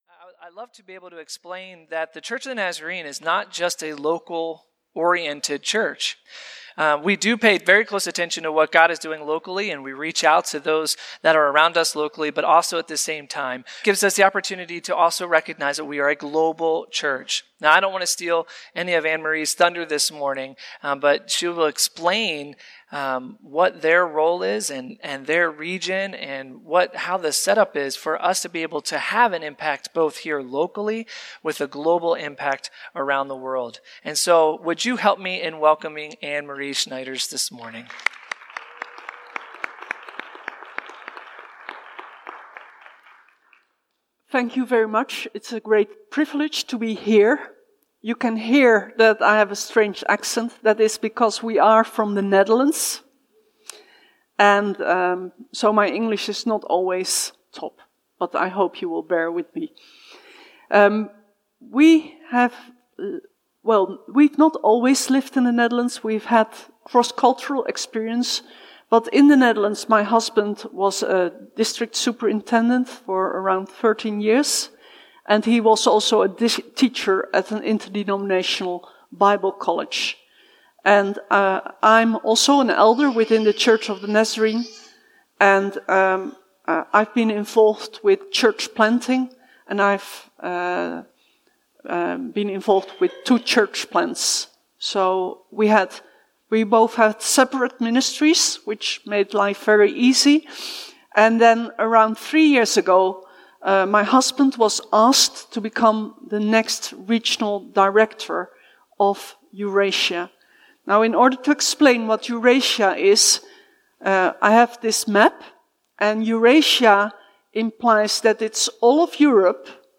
Chicago Central District, Mission Blitz Sunday.